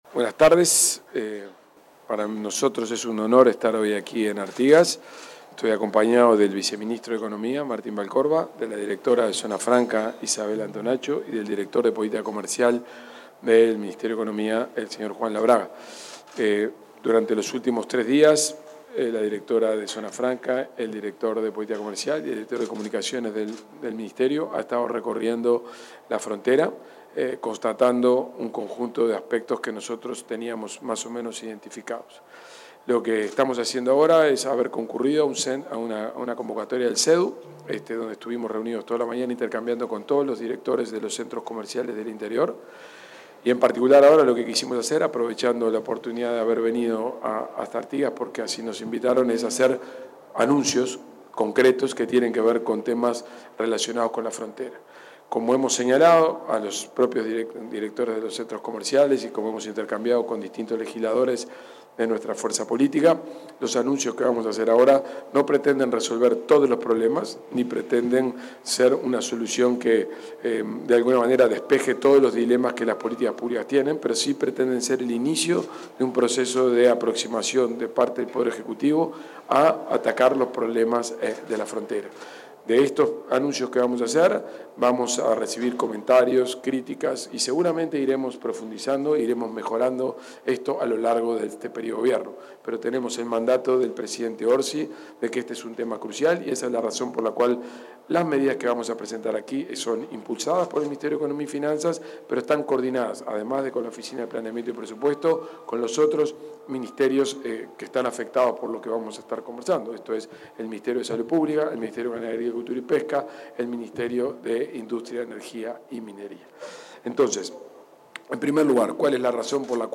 Declaraciones del ministro y el subsecretario de Economía, Gabriel Oddone y Martín Vallcorba
Declaraciones del ministro y el subsecretario de Economía, Gabriel Oddone y Martín Vallcorba 05/06/2025 Compartir Facebook X Copiar enlace WhatsApp LinkedIn El ministro de Economía y Finanzas, Gabriel Oddone, y el subsecretario de la cartera, Martín Vallcorba, informaron y respondieron preguntas sobre las medidas que contemplan la situación comercial y económica en la frontera con Brasil, en una conferencia de prensa realizada en Artigas, luego de que se reunieran con comerciantes y empresarios de la zona.